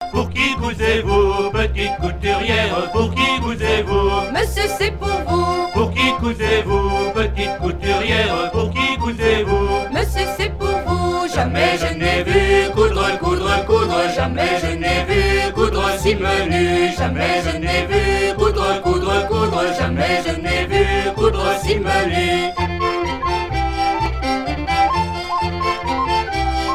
- 007415 Thème : 0074 - Divertissements d'adultes - Couplets à danser Résumé : Pour qui cousez-vous, petite couturière, pour qui cousez-vous ?
danse : ronde